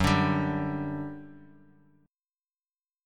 G5/F# chord {2 5 5 x x x} chord